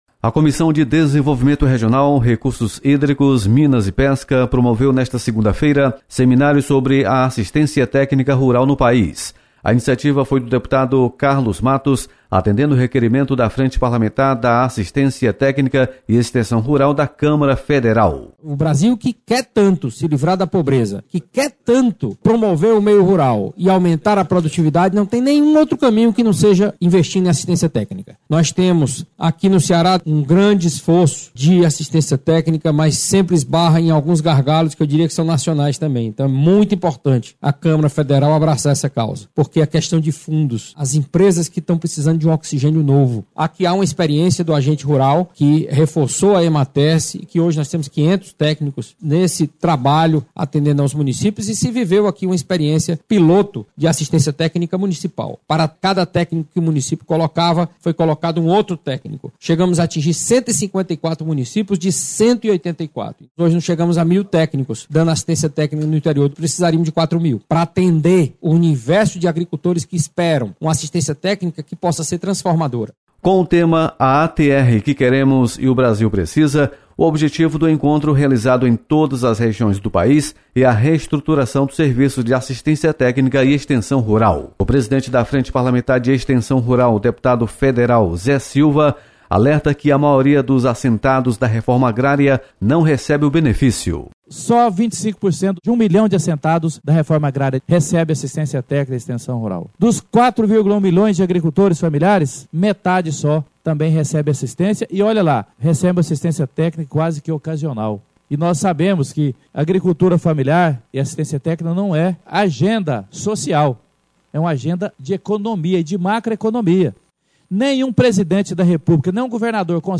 Comissão de Desenvolvimento Regional promove seminário sobre assistência rural. Repórter